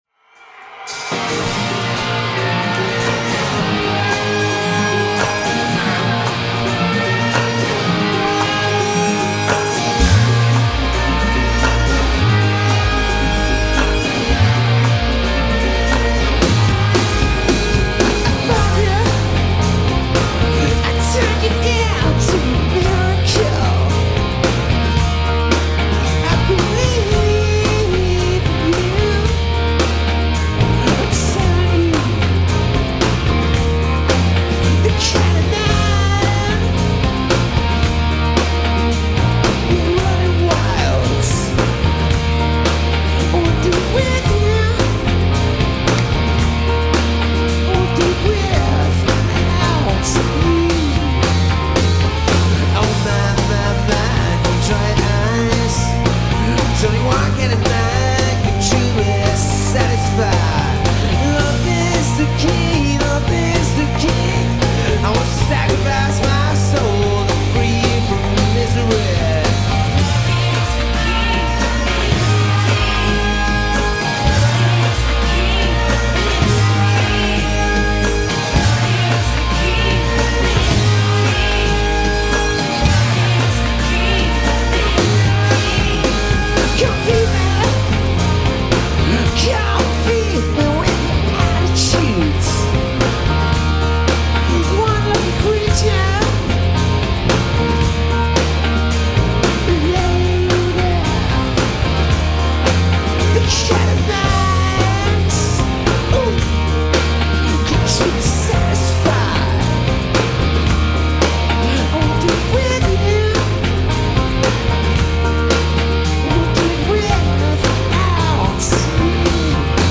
recorded at the Birmingham Academy
recorded in concert at the Birmingham Academy in 2001